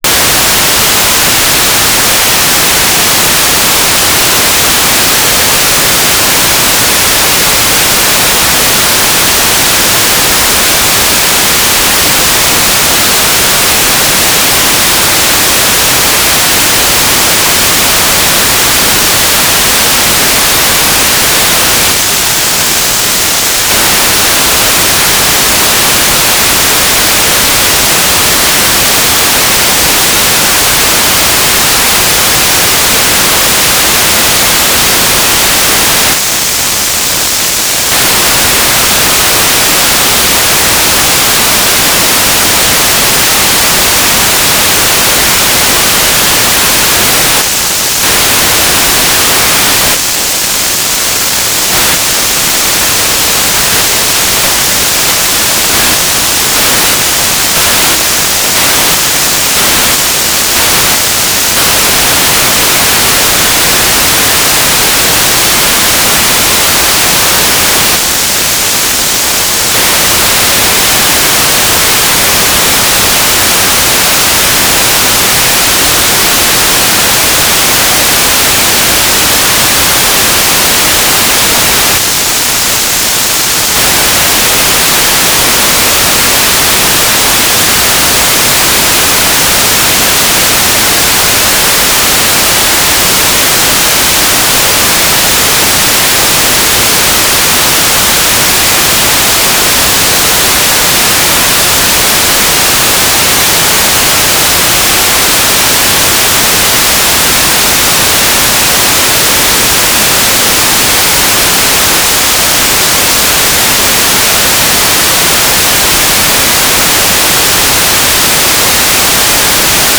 "transmitter_description": "Mode U - GMSK2k4 - USP",
"transmitter_mode": "GMSK USP",